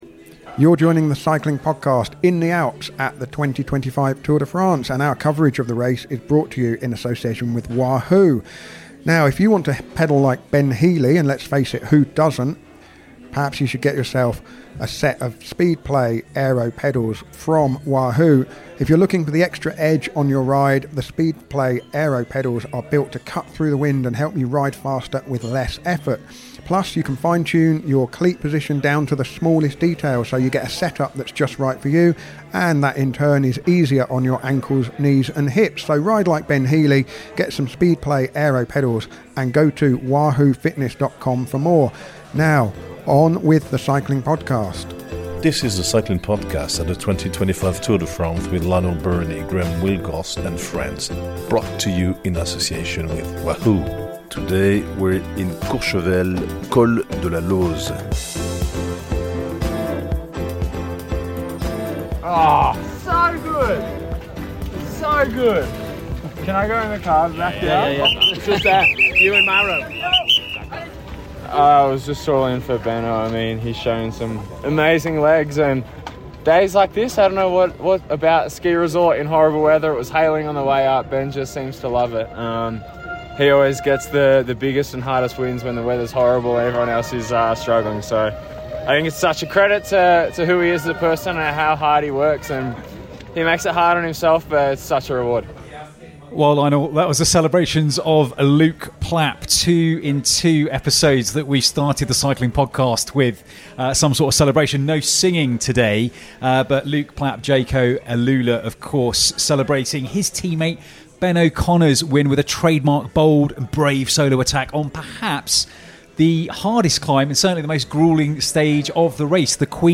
We’re back on the road for the 13th time with daily episodes recorded at the heart of the world’s biggest race. Our nightly episodes feature race analysis, interviews and plenty of French flavour.